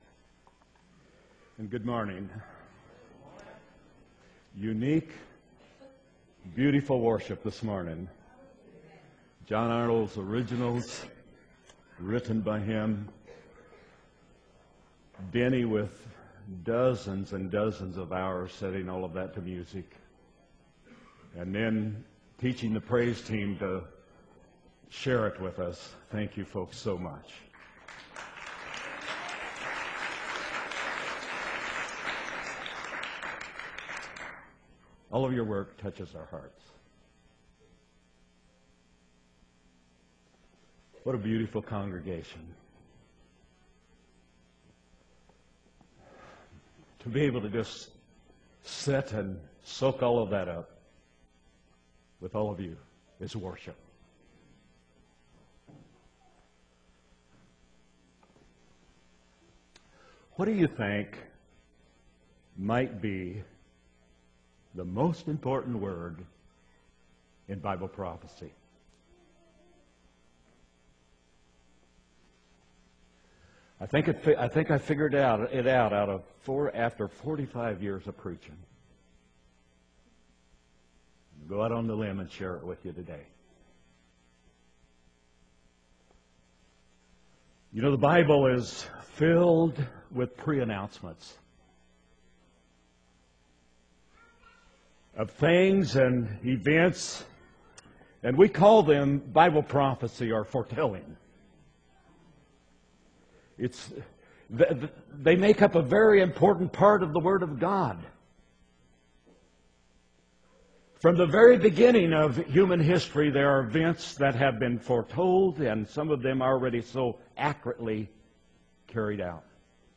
3-3-12 sermon